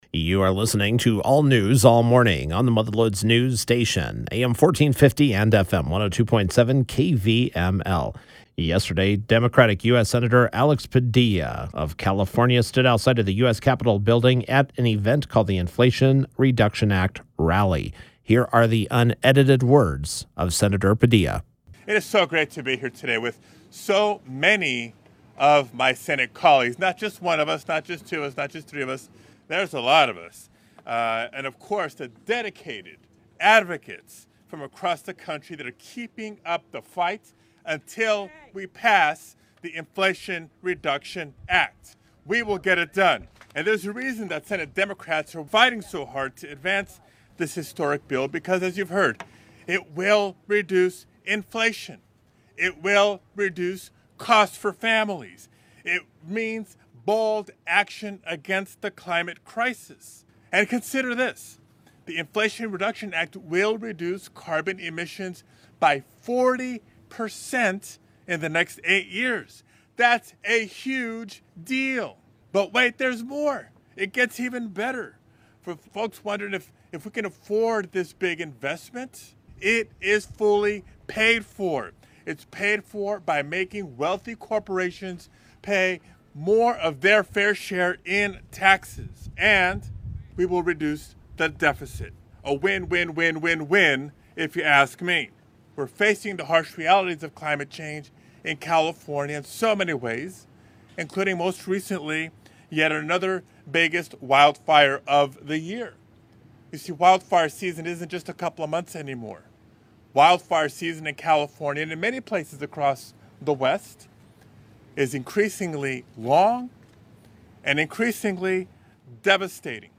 As Senate Democrats work to advance the Inflation Reduction Act this week, U.S. Senator Alex Padilla (D-Calif.) joined several climate, health care, and tax fairness advocates at a press conference at the U.S. Capitol to highlight the legislation.